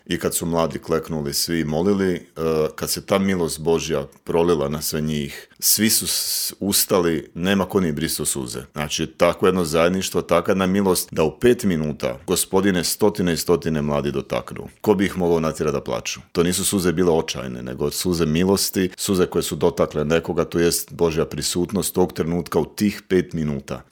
Cijeli intervju možete pronaći na YouTube kanalu Media servisa.